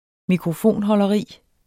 Udtale [ -hʌlʌˌʁiˀ ]